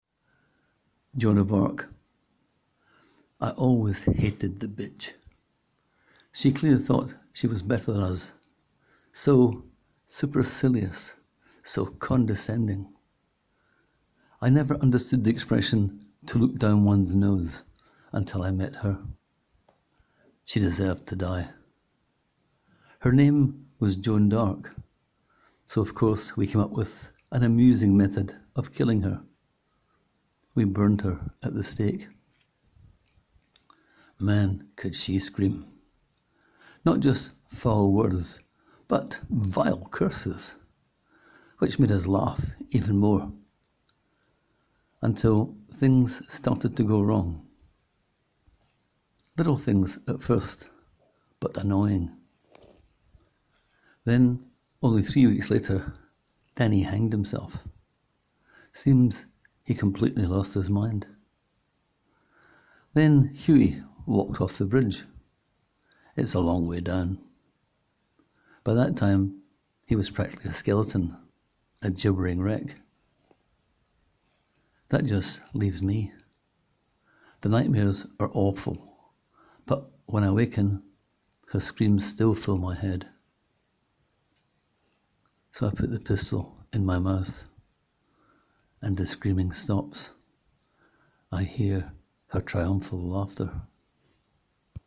Click here to hear the author read the tale: